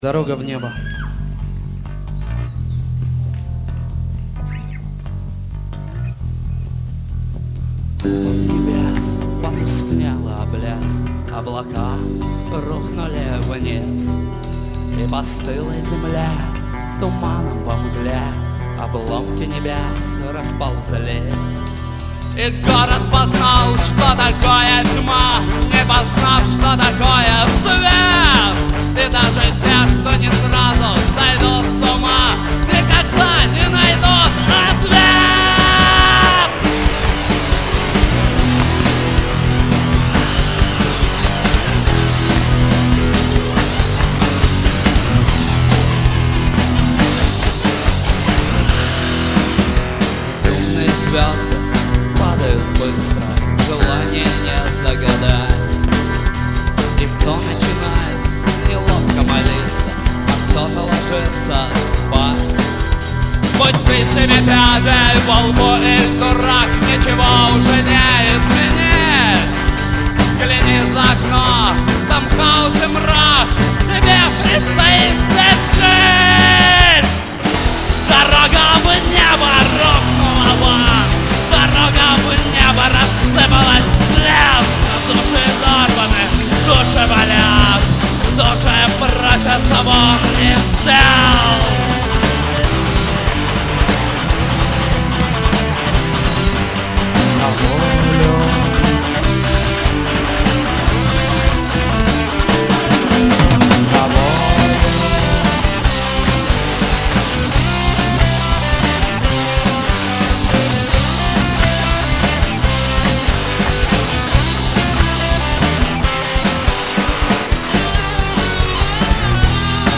Запись с концерта в г. Багратионовск.